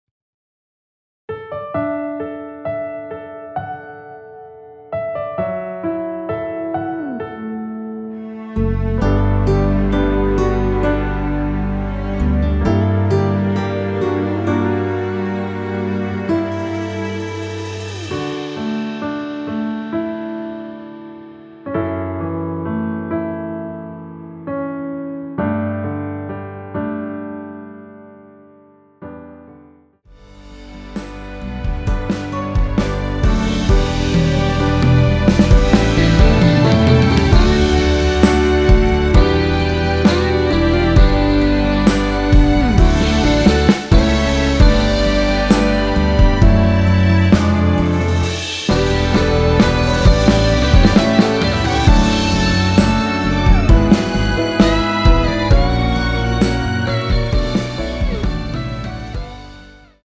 원키에서(-1)내린 MR입니다.
D
앞부분30초, 뒷부분30초씩 편집해서 올려 드리고 있습니다.